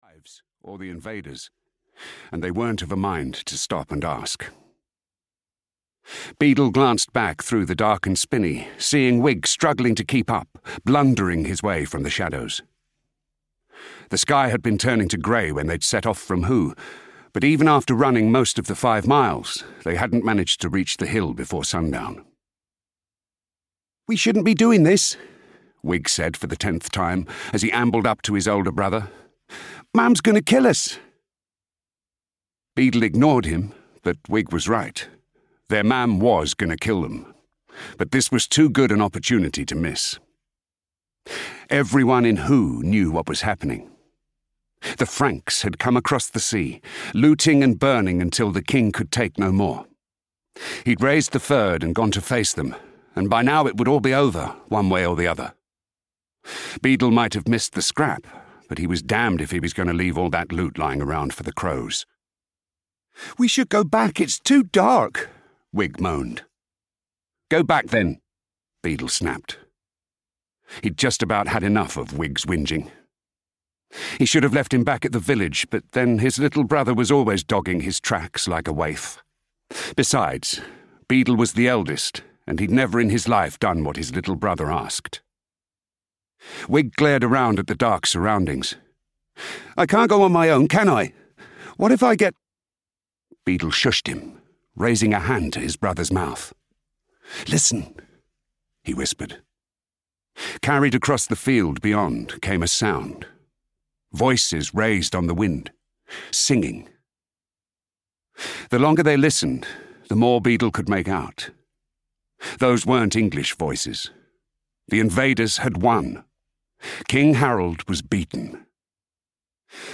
Oath Bound (EN) audiokniha
Ukázka z knihy